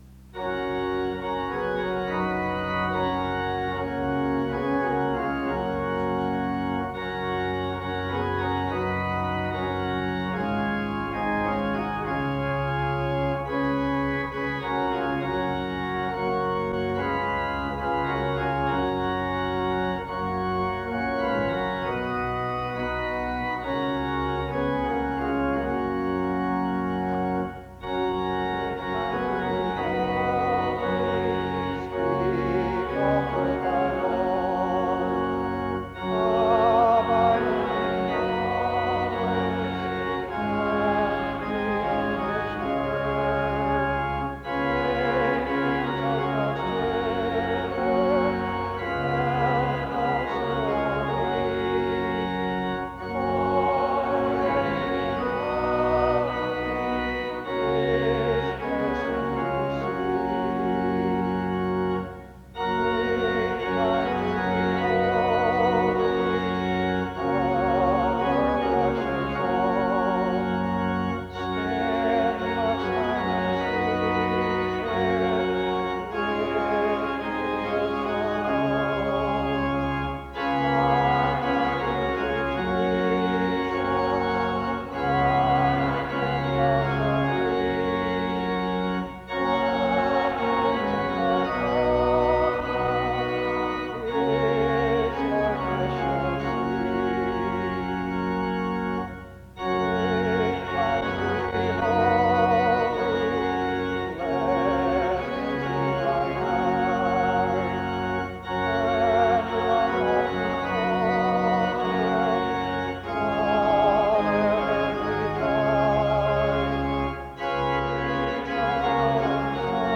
The service begins with music and singing from 0:00-2:56. A responsive reading takes place from 3:03-5:34. A prayer is offered from 5:34-7:51.
SEBTS Chapel and Special Event Recordings SEBTS Chapel and Special Event Recordings